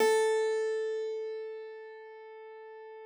53l-pno13-A2.wav